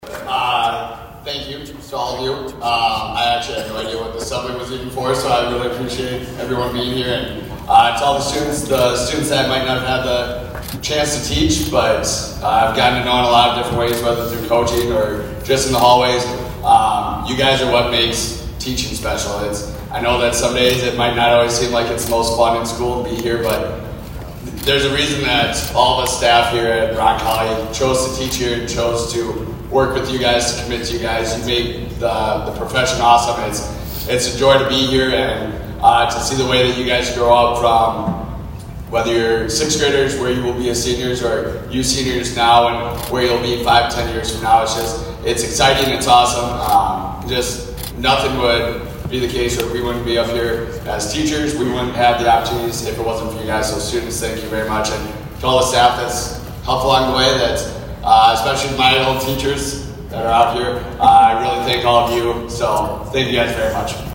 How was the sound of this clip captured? ABERDEEN, S.D.(HubCityRadio)- Students and faculty were in for a surprise of lifetime Thursday at Aberdeen Roncalli Middle & High School.